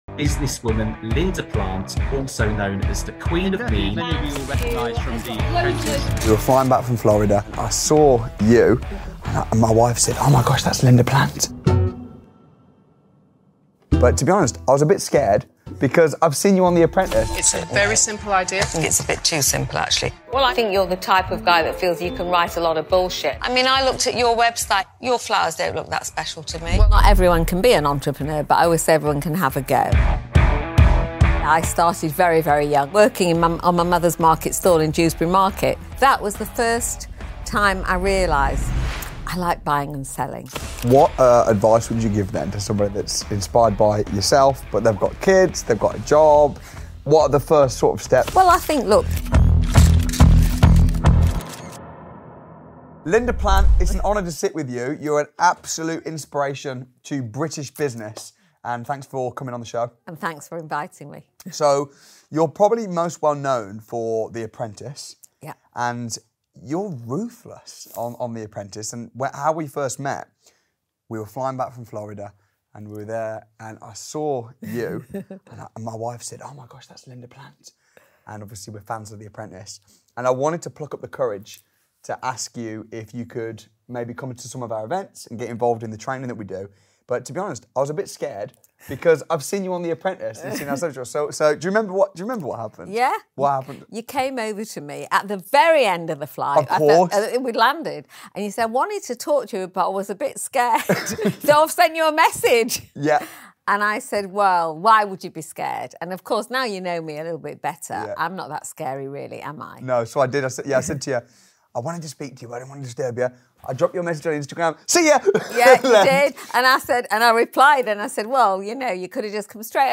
Linda Plant’s No-Nonsense Business Advice | Interview with Linda Plant